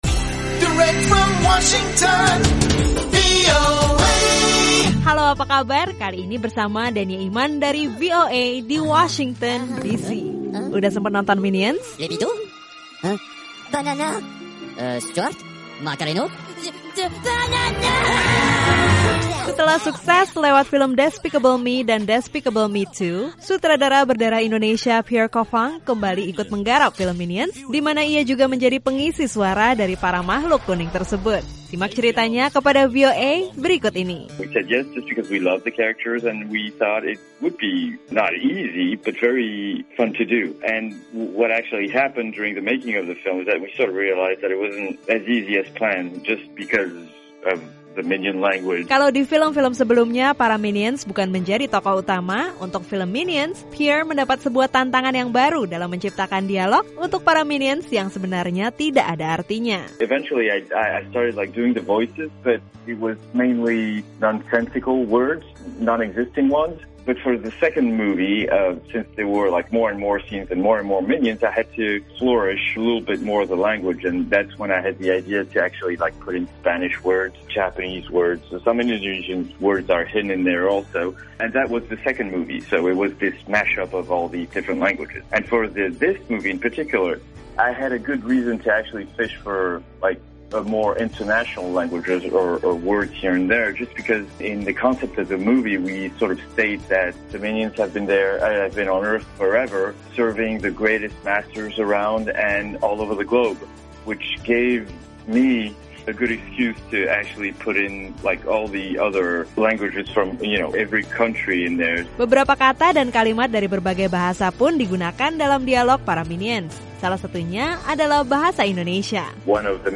Lewat obrolan bersama VOA Scoop, sutradara film Minions yang berdarah Indonesia, Pierre Coffin, berbagi cerita tentang film Minions, tantangan yang ia hadapi selama penggarapan, bahasa Indonesia yang digunakan, dan komentarnya mengenai isu bahwa film ini mengandung ajaran sesat.